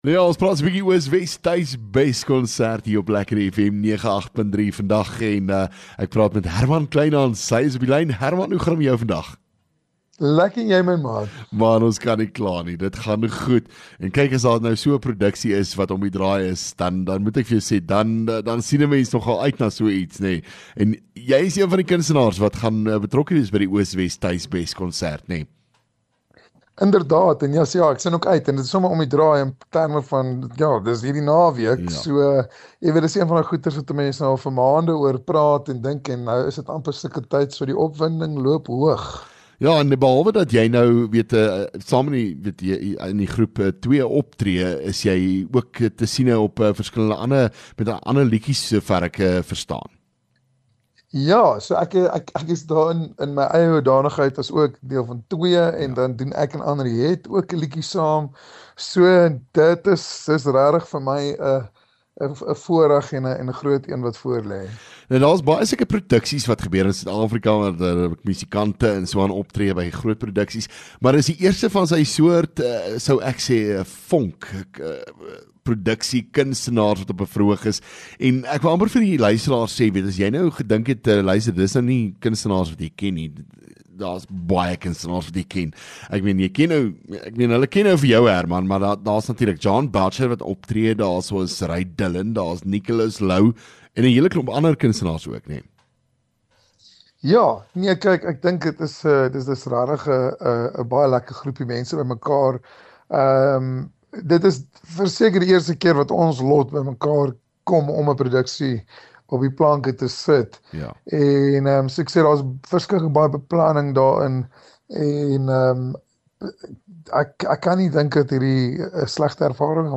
LEKKER FM | Onderhoude 31 Mar Oos Wes Tuis Bes